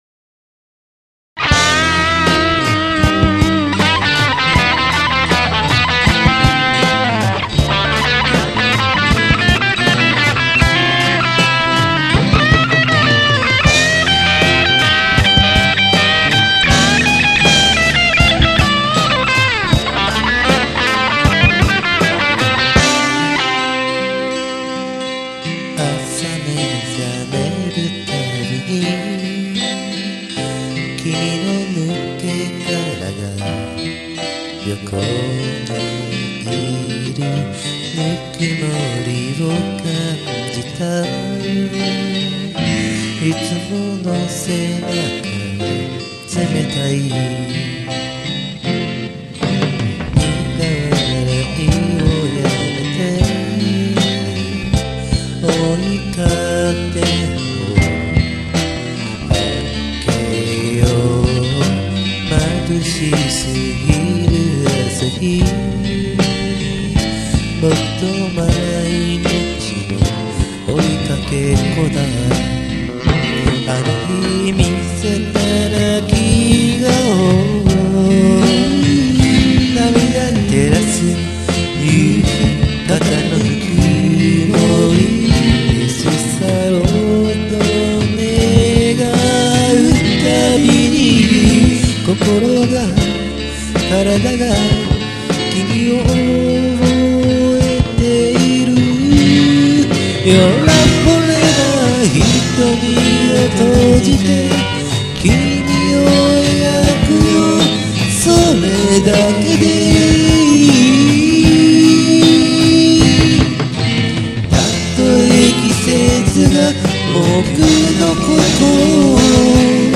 ドラム  Pistoncollage
キーボード
マイナーセブンスコードの多い曲っすわ・・・
聞いてたんがギターだけの伴奏なんで、キーボード、エレキ、ベース、アコは勝手に作っちゃいました。
エフェクトは、デジタルディレイ、コンプレッションサスティナー、ブルースオーバードライブです。
途中で、ブルースオーバードライブを切って、フェイズシフターを入れてます。